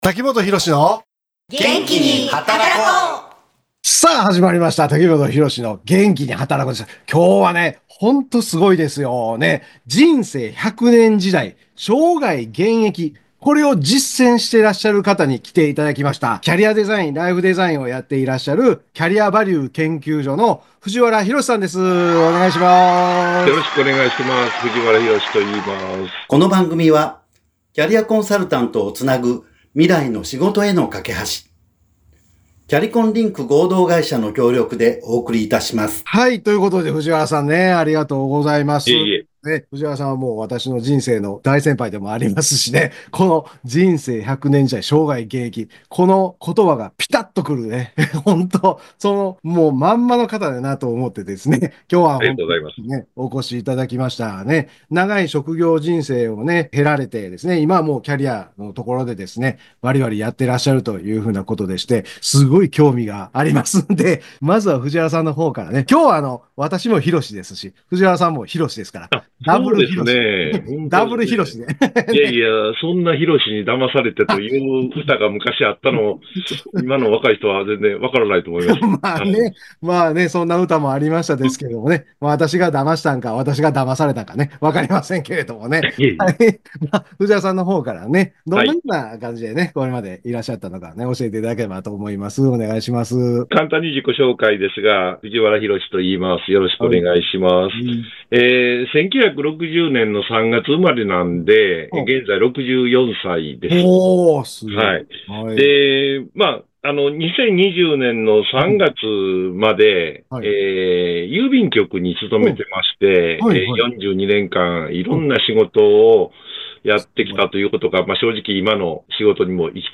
キャリアデザインとネットワークの重要性についての実体験が詰まったインタビュー内容。